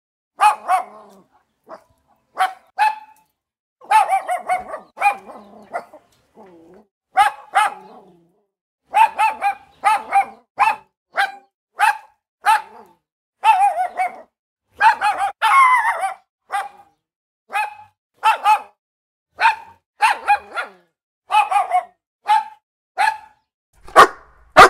Cute Puppy Dog Barking